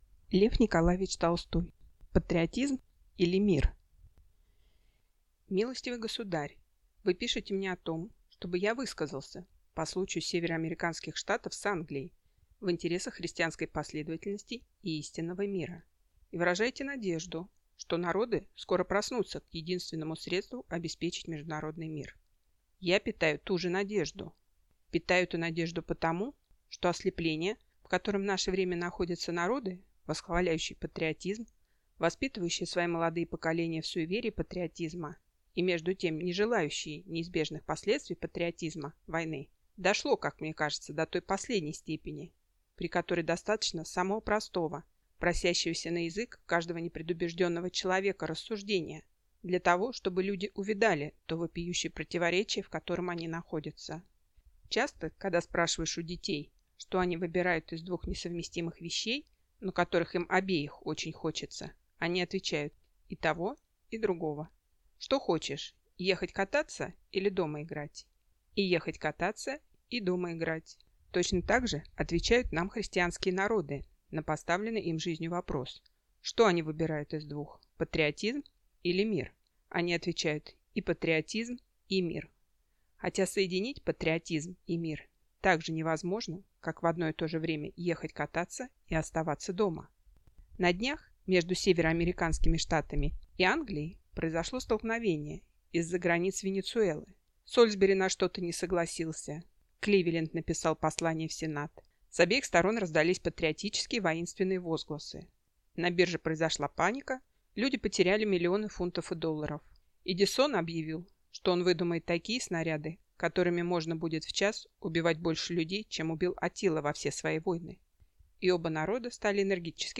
Аудиокнига Патриотизм или Мир?